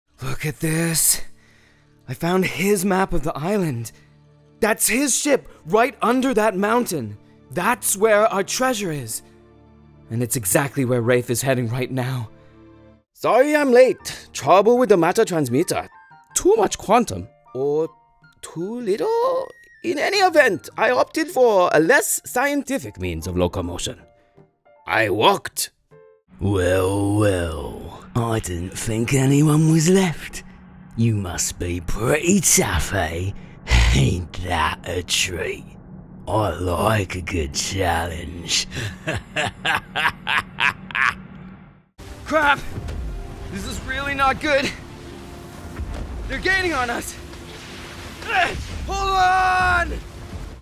Video Game Showreel
Male
American Standard
British RP
Confident
Friendly
Youthful
Warm
Upbeat